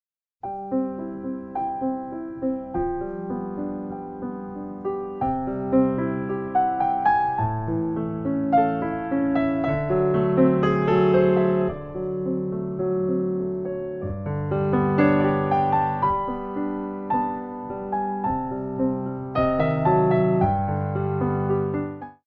By Pianist & Ballet Accompanist
Slow Tendu
SAB style